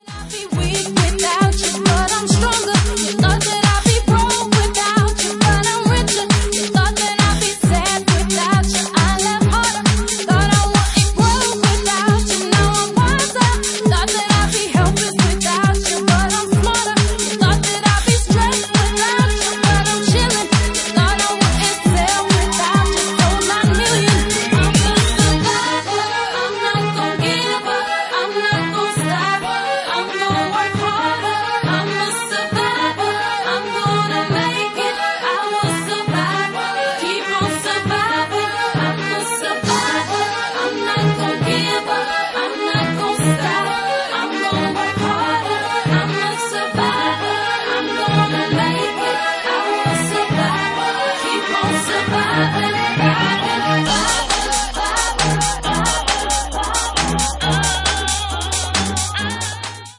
Genre:Bassline House
- Bassline House at 135 bpm